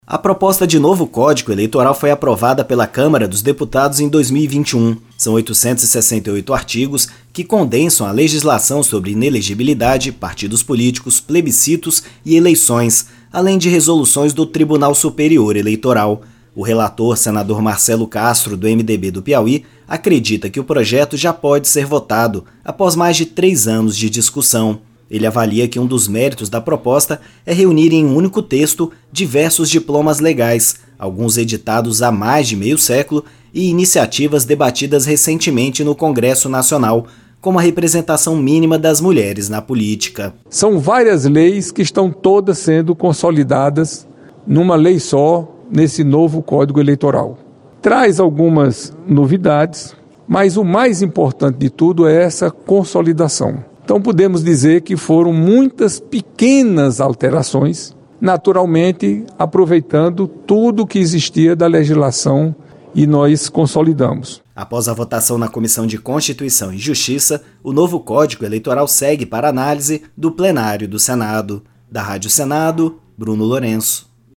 O relator, Marcelo Castro (MDB-PI), explica que o texto consolida várias regras relacionadas a questões eleitorais e representação política. Após a votação na CCJ, a proposta do novo Código Eleitoral seguirá para a análise do Plenário do Senado.